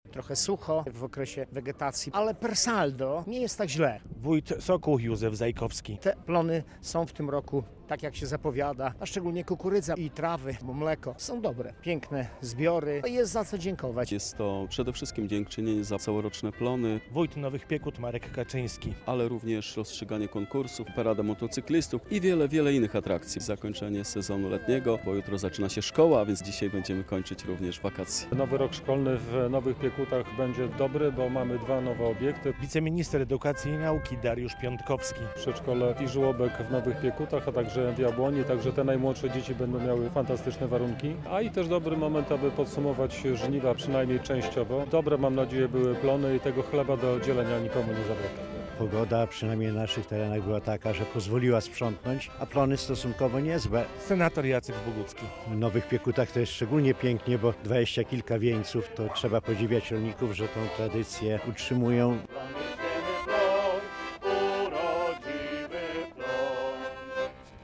To tradycyjna forma podziękowania za plony i okazja do wspólnej zabawy - w Nowych Piekutach i w Sokołach w niedzielę (3.09) odbyły się imprezy dożynkowe.
relacja